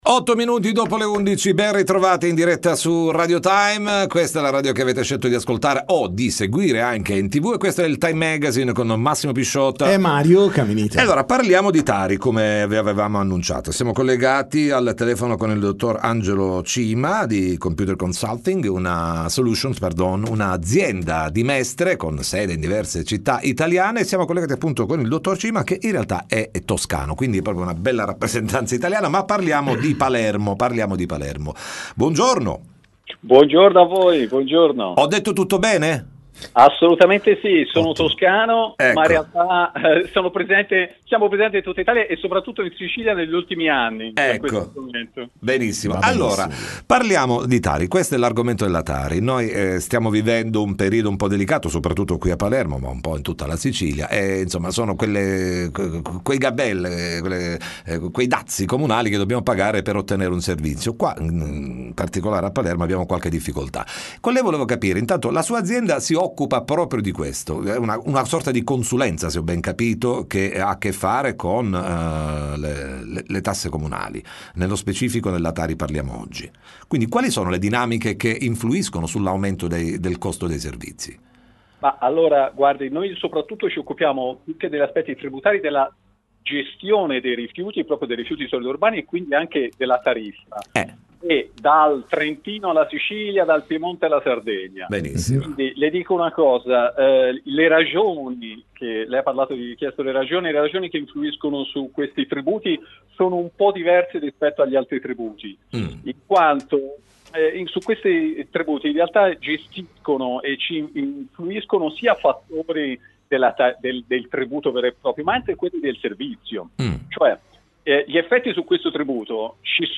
Interviste Time Magazine